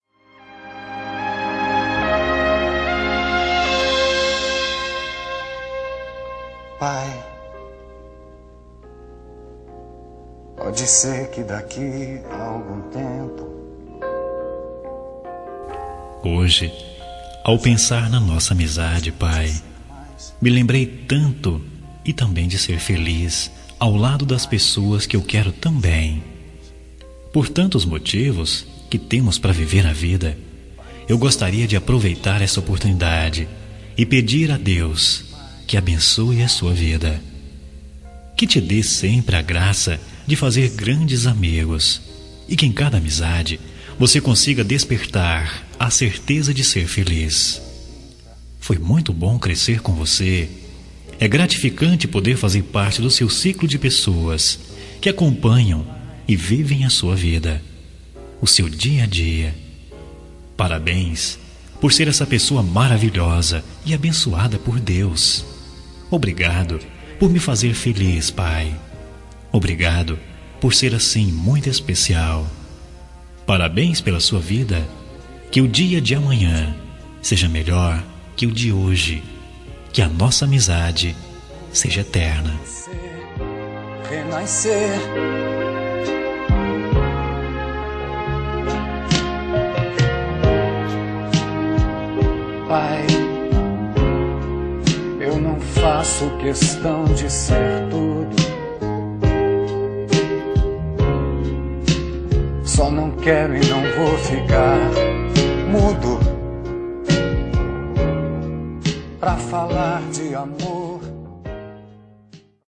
Temas com Voz Masculina